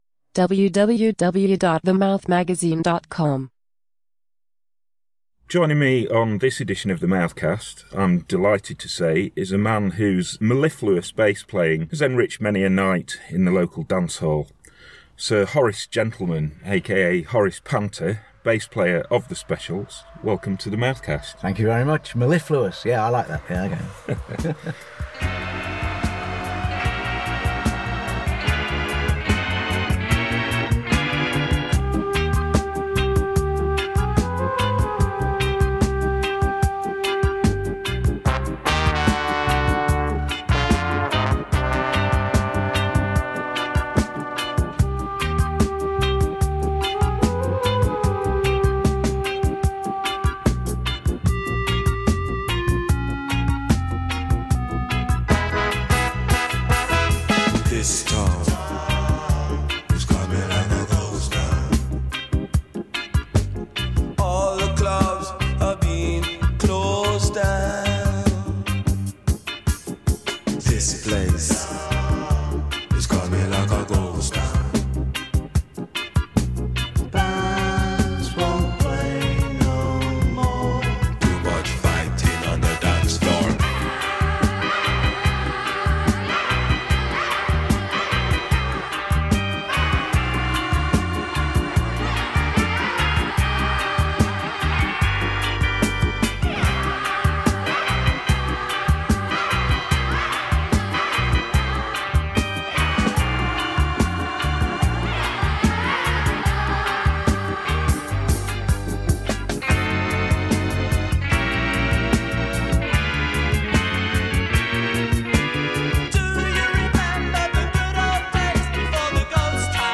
Bass player Horace Panter – a founding member of The Specials – features in this new edition of The Mouthcast recorded at the band’s hotel before a show on the current run of UK dates . He reflects on the impact of GHOST TOWN, and talks about how parallels can be drawn between the time it was written and the current state of the UK. He also discusses the idea of bands ‘of a certain age’ reforming, and gives an insight into his ‘parallel career’ in art…